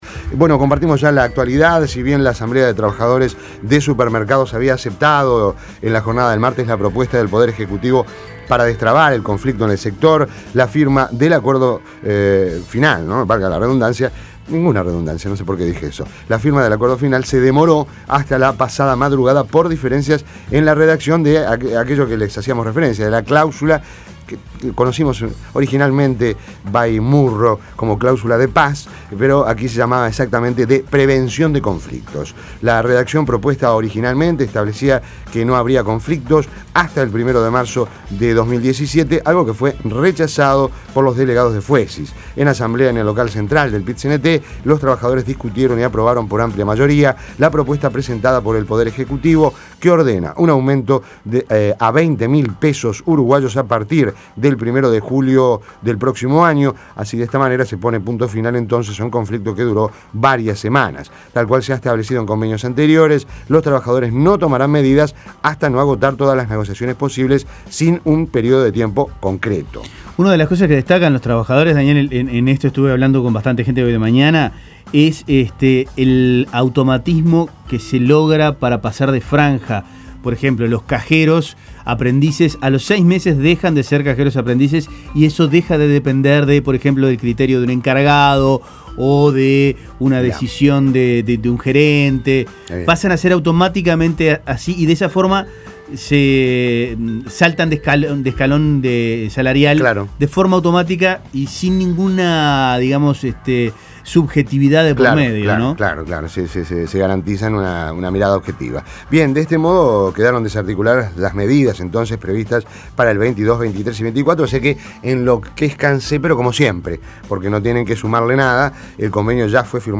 Las principales noticias del día, resumidas en la Primera Zona de Rompkbzas.